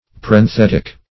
parenthetic \par`en*thet"ic\ (p[a^]r`[e^]n*th[e^]t"[i^]k),
parenthetic.mp3